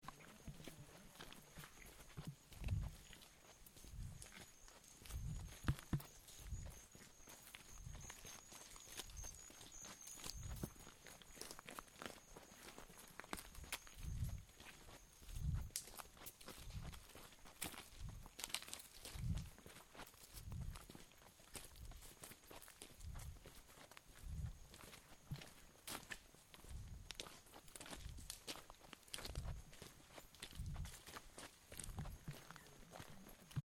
Voici donc un petit reportage sonore de notre périple :
Nous marchons :